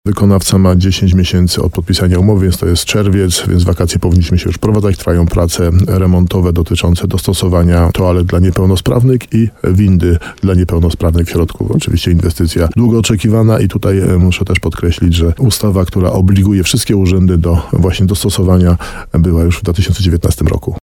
Wiele ma się zmienić, bo pomieszczenia administracyjne zostaną dostosowane do nowych przepisów przeciwpożarowych, ale też będą bardziej przystępne dla osób z niepełnosprawnościami – mówi starosta nowosądecki Tadeusz Zaremba.